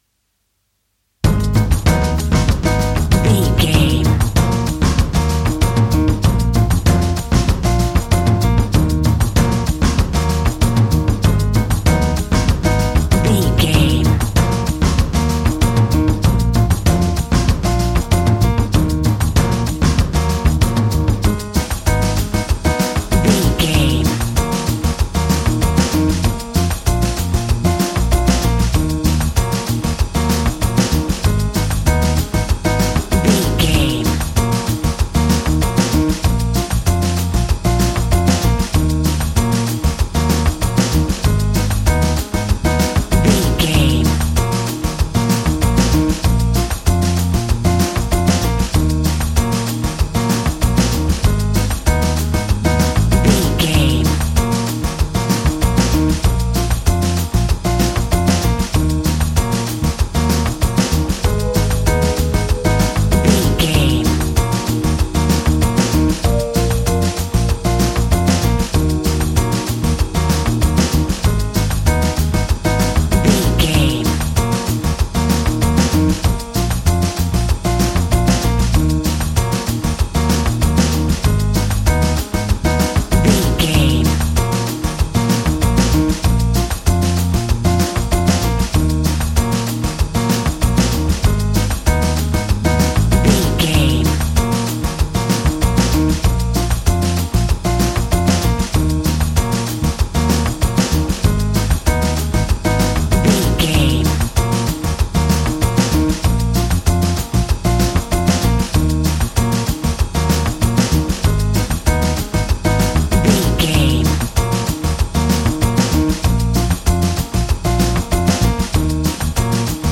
Aeolian/Minor
E♭
funky
energetic
romantic
percussion
electric guitar
acoustic guitar